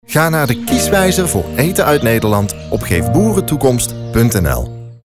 Radiocommercials
Download hier de originele sound uit de commercial als ringtoon.
Bedreigd-Tagon-Kieswijzer-5sec-preview-1.0.mp3